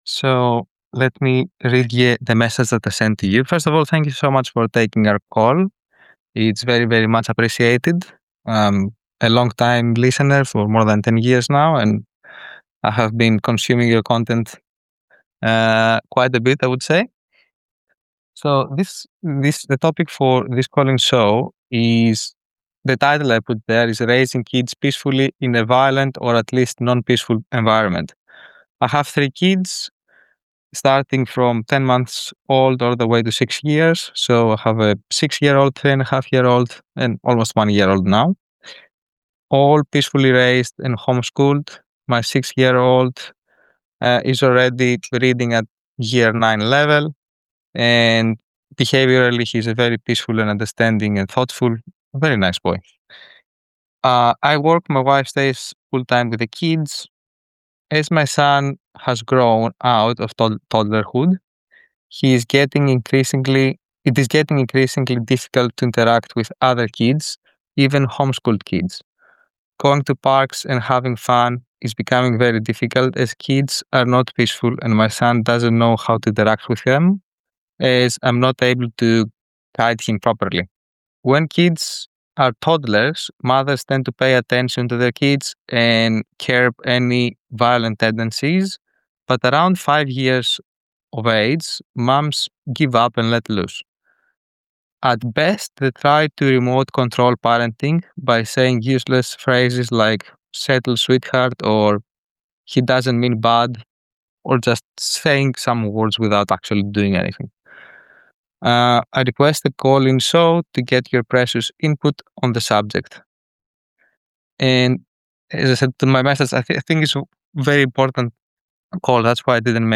CALL IN SHOW